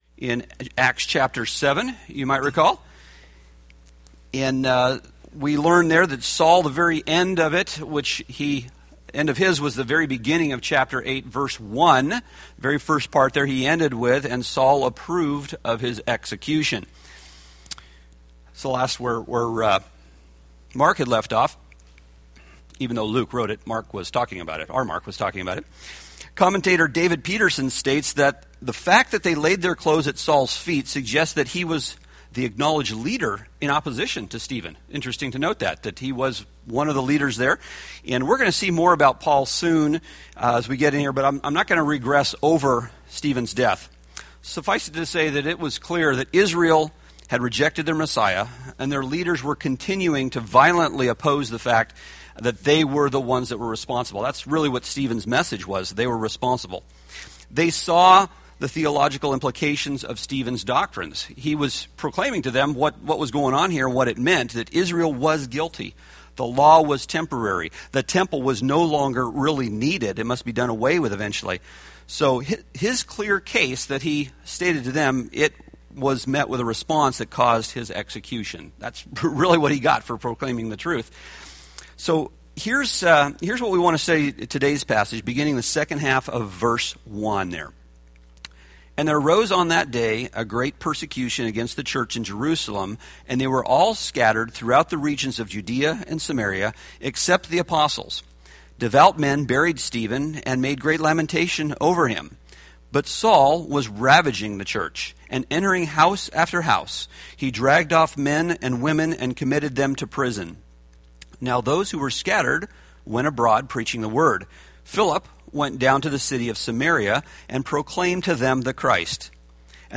Date: Mar 16, 2014 Series: Acts Grouping: Sunday School (Adult) More: Download MP3